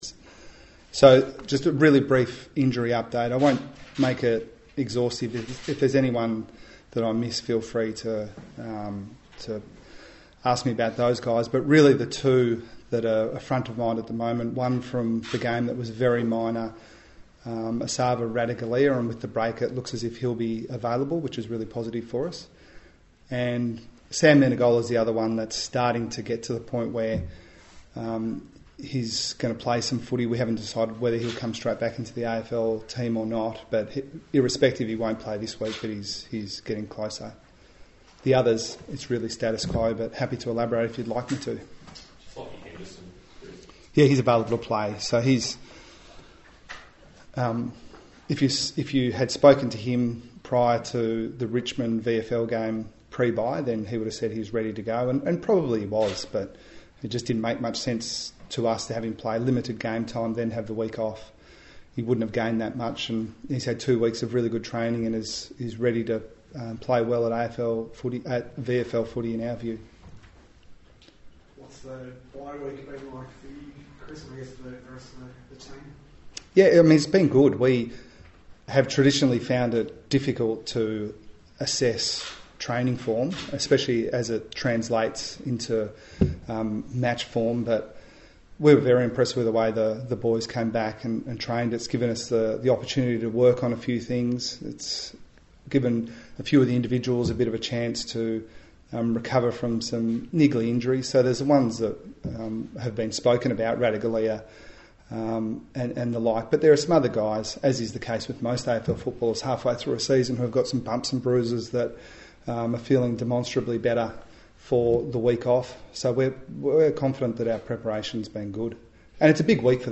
Geelong coach Chris Scott faced the media ahead of Saturday night's clash with Port Adelaide.